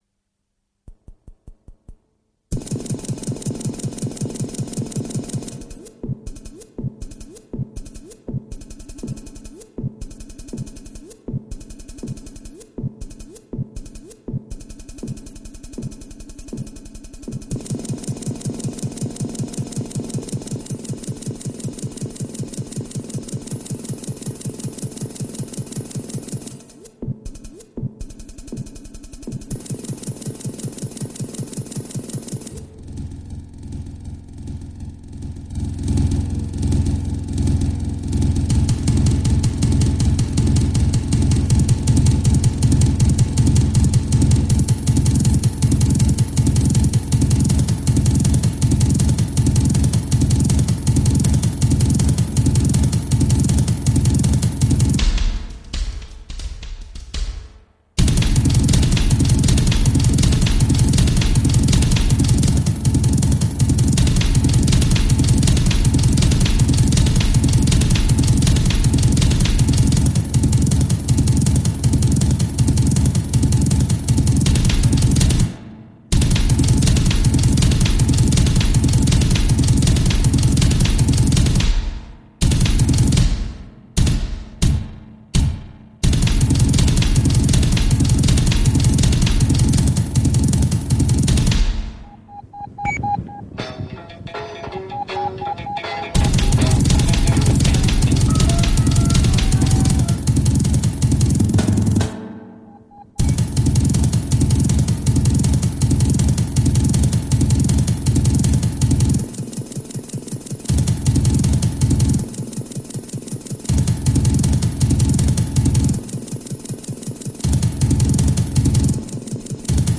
Stereo, Sound Level D (much better)